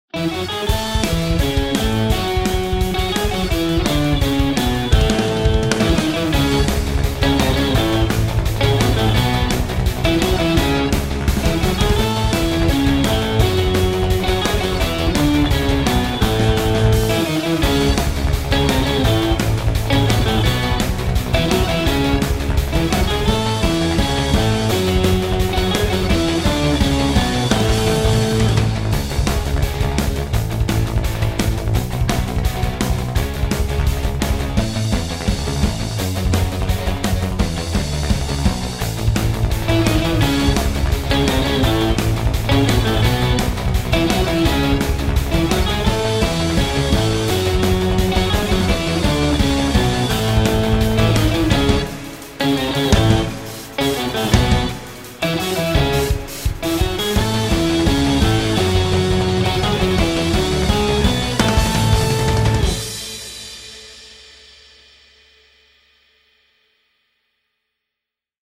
エレキギターとドラムが元気に駆け抜ける、ちょっぴりコミカルでポップな仕上がりです。
• テンポ： 約150BPMの軽快な8ビート
• キー： Cメジャー（原曲と同じ）
• 構成： Aメロ → Bメロ → サビ風展開 → エンディング
• ミックス： 中域のバランスを意識し、子供向け再生環境（スマホ・タブレット）でもしっかり聴こえるように調整。
シンプルな構成ながら、楽器の鳴らし方やパンの振り方でライブ感を出しています。
アレンジ ロック 童謡 ギター